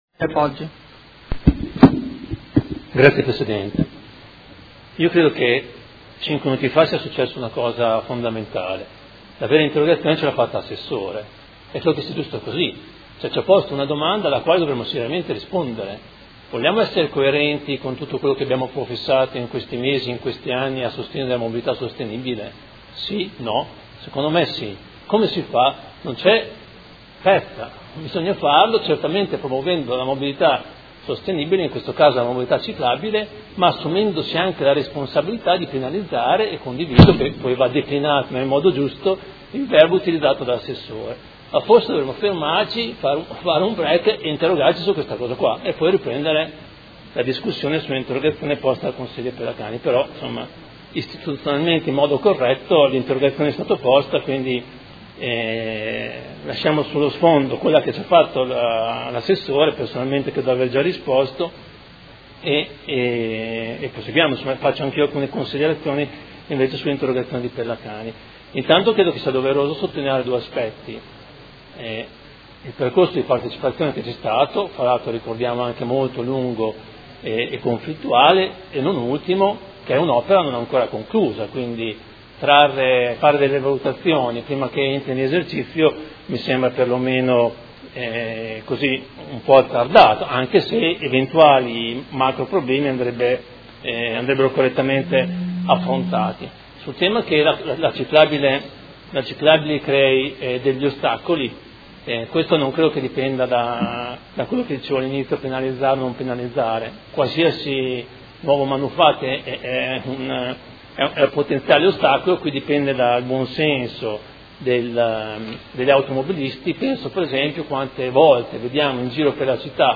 Seduta del 31/03/2016. Interrogazione del Gruppo Consiliare Forza Italia avente per oggetto: Restringimento della carreggiata di Via Pietro Giardini.